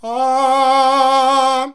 AAAAAH  C.wav